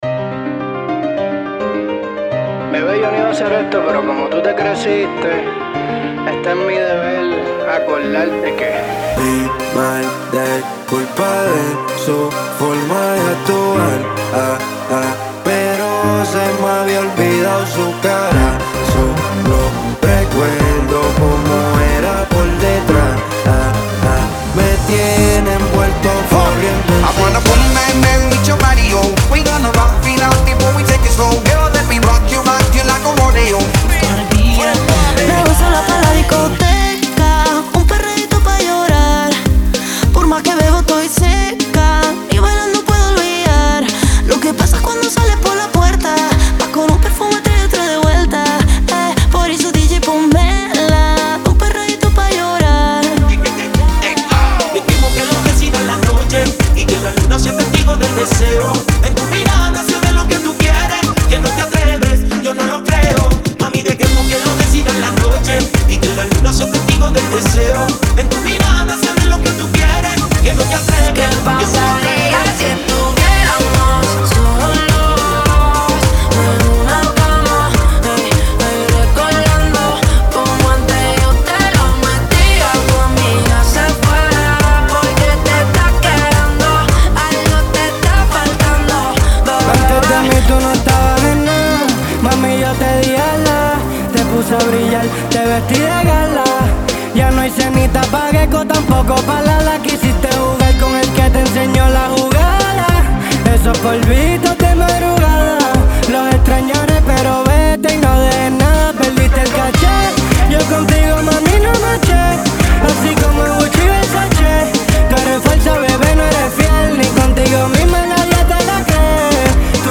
(Acapella e Instrumental)